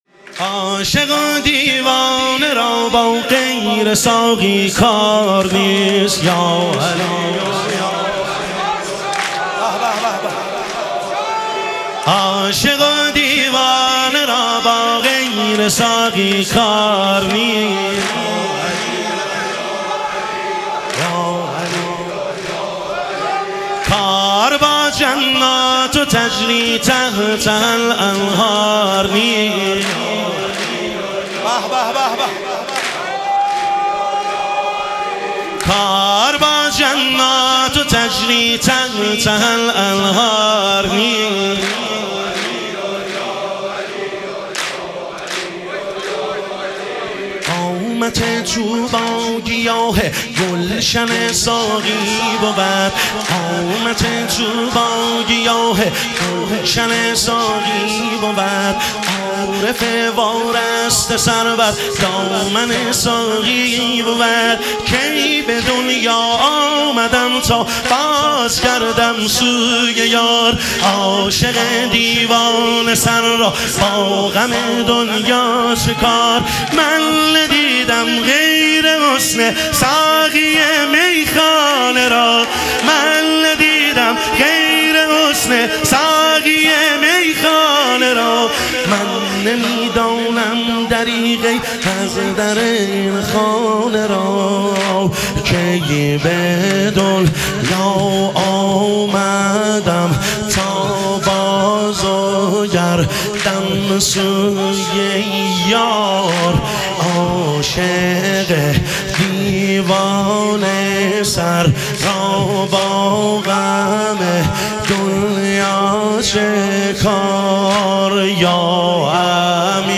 اربعین امیرالمومنین علیه السلام - واحد